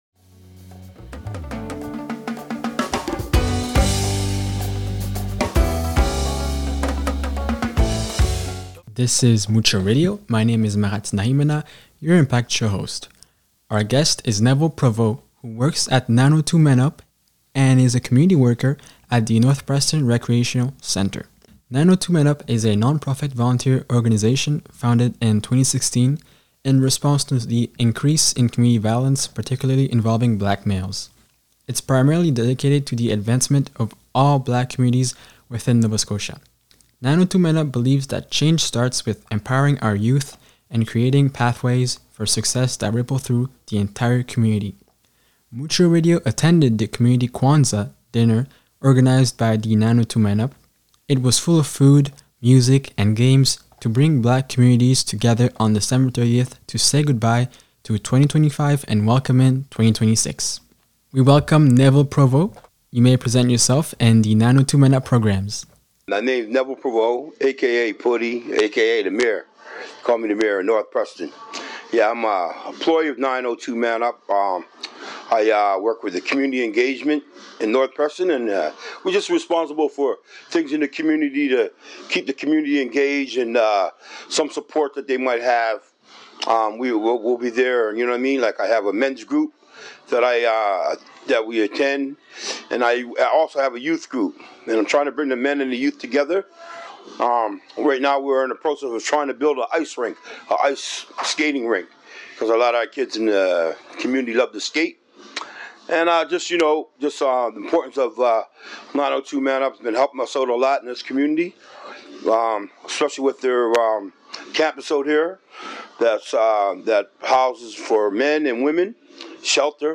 It’s primarily dedicated to the advancement of all Black communities within Nova Scotia. 902 Man Up believes that change starts with empowering our youth and creating pathways for success that ripple through the entire communities . Mutcho Radio attended the community Kwanzaa dinner organized by 902 Man Up, full of food, music and games to bring black communities together on December 30th to say goodbye to 2025 and welcome 2026.